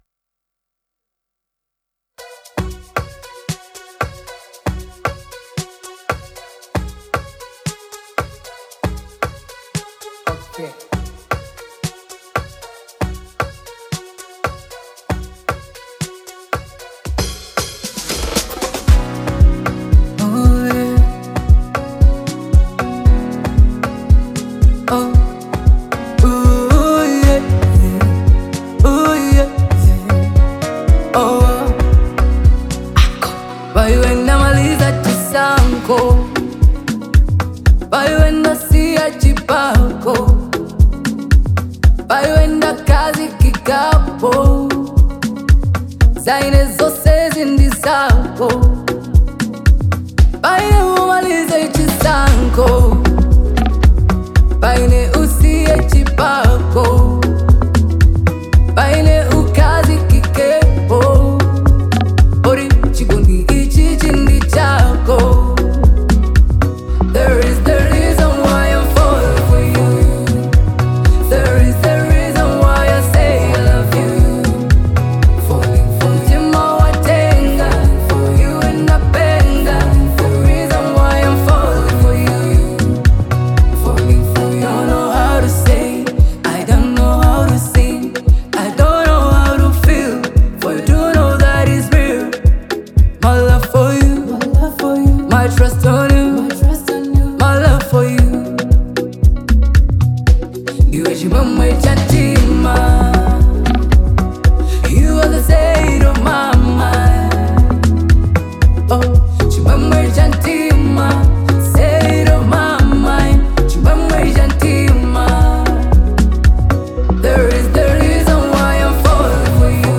Afro